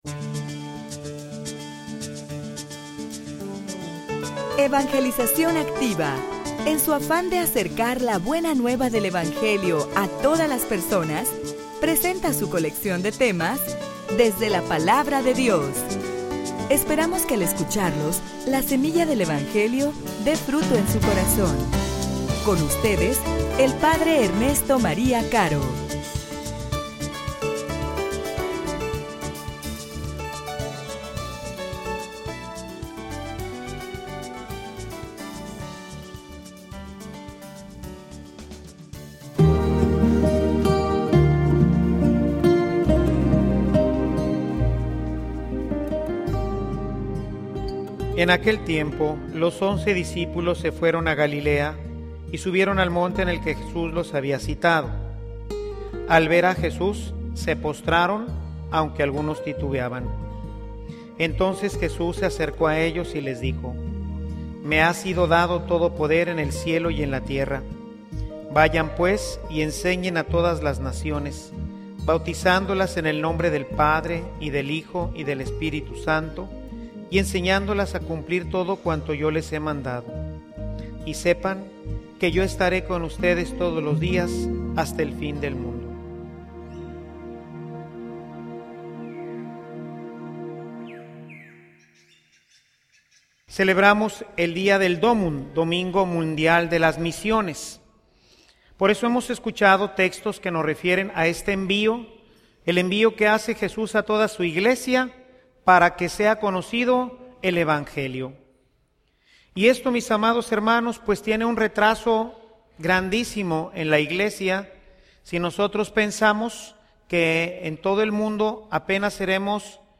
homilia_Quien_ira_Quien_llevara_el_mensaje.mp3